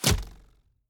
Bow Blocked 1.wav